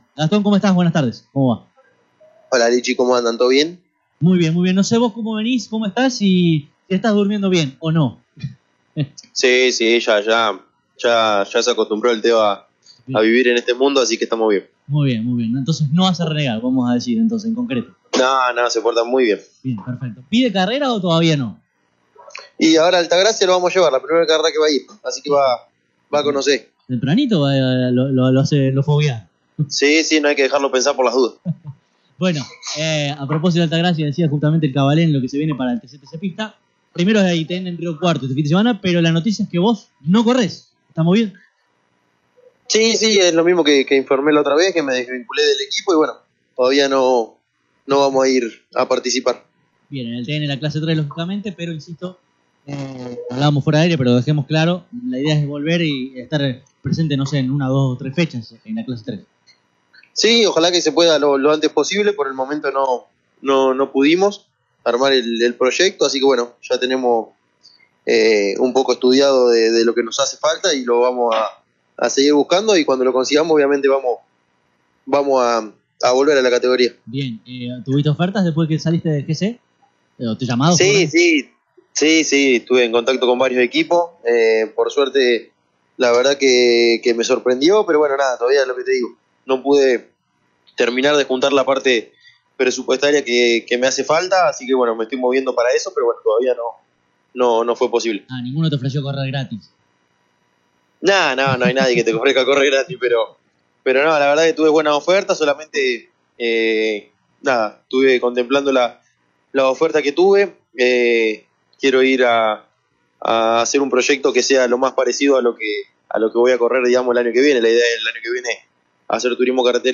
Córdoba Competición mayo 21, 2025 AUDIOS, Clase 3, TN